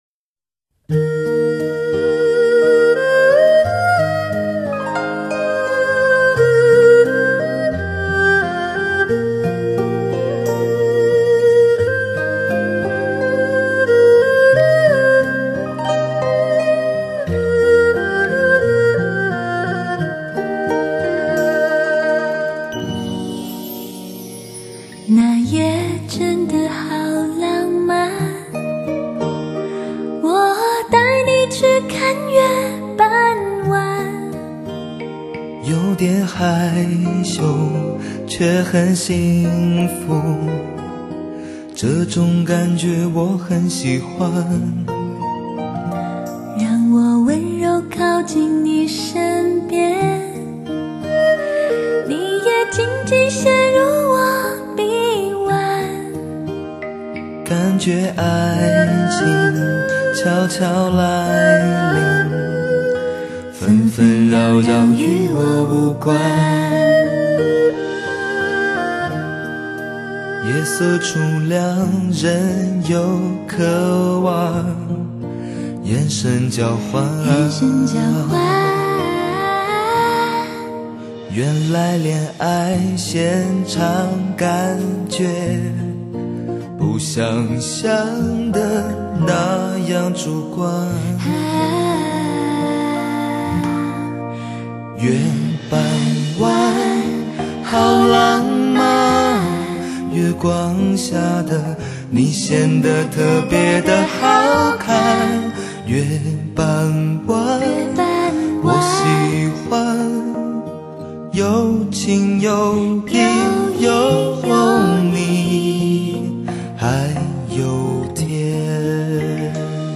没有抹掉时代符号，潮流仍在，只是敛去骚动， 留下温婉安慰。